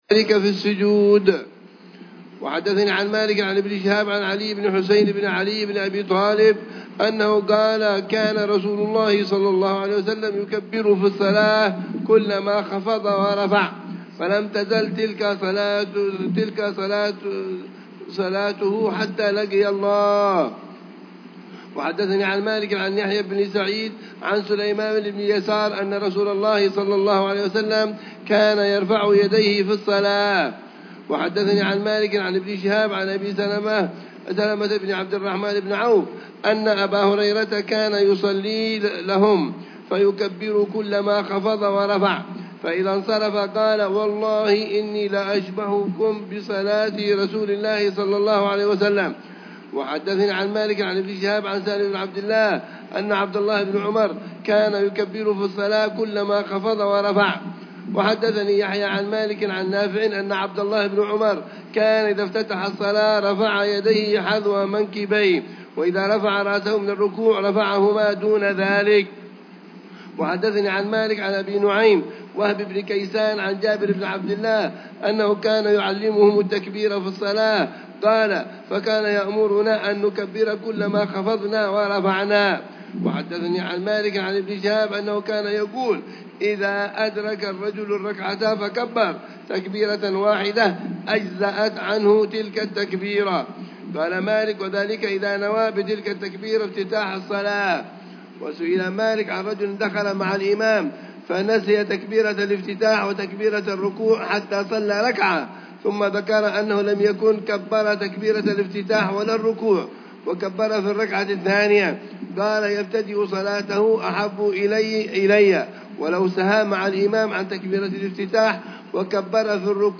شرح الحبيب العلامة عمر بن محمد بن حفيظ على كتاب الموطأ لإمام دار الهجرة الإمام مالك بن أنس الأصبحي، برواية الإمام يحيى بن يحيى الليثي، كتاب ال